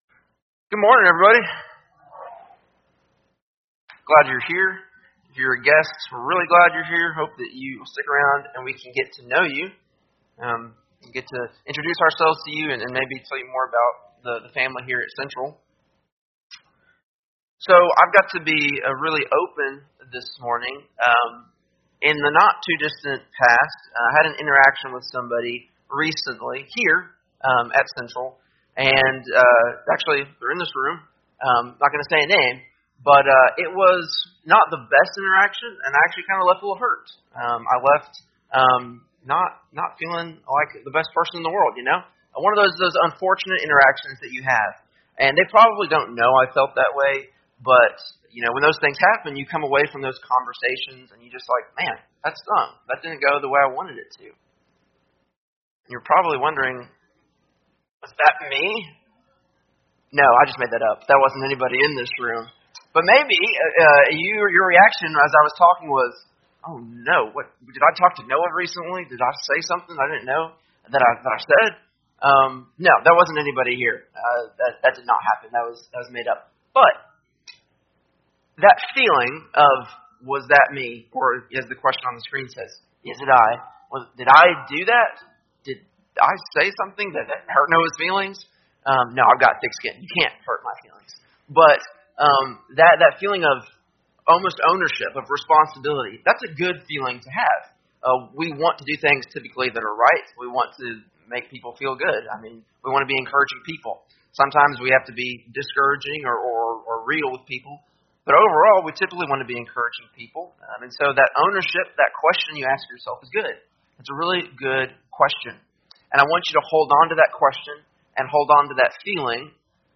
Sunday AM Sermon
8-18-24-Sunday-AM-Sermon.mp3